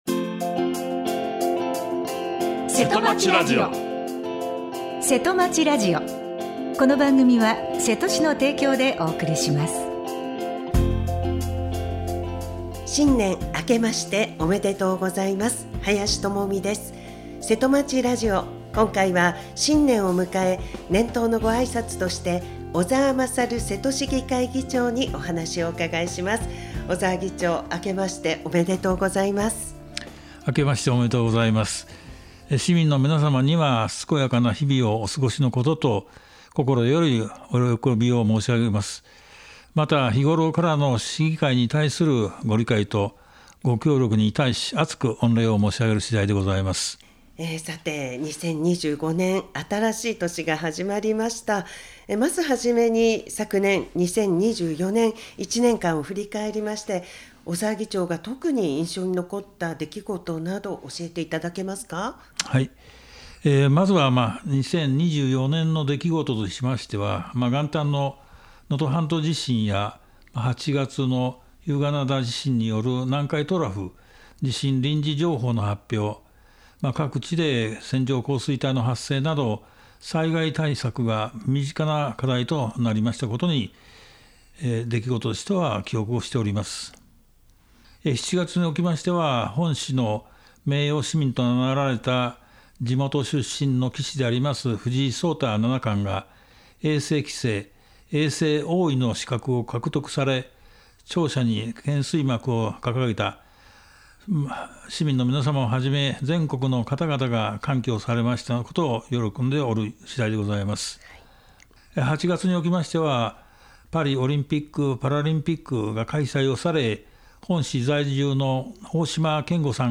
今回のせとまちラジオは、瀬戸市議会議長の小澤勝さんからの新年のごあいさつです。